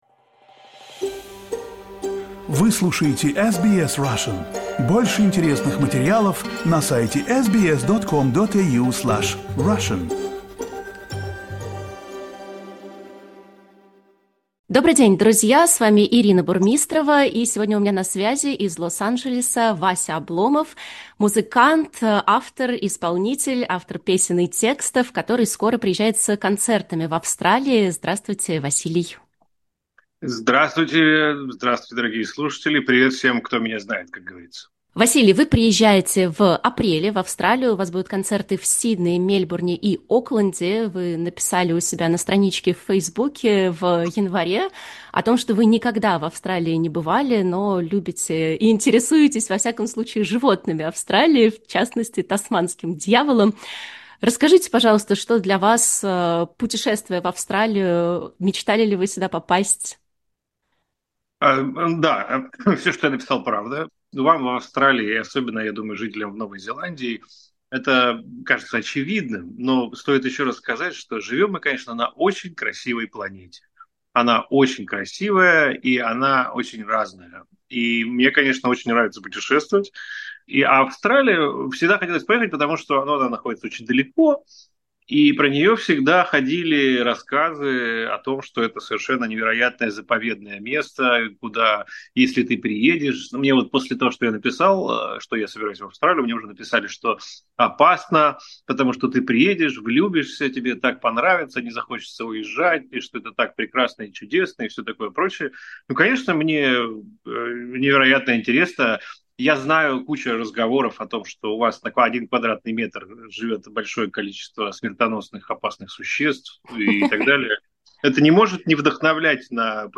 We had a chat about Australia, tours around the world, the status of a “foreign agent”, as well as Alexey Navalny's figure.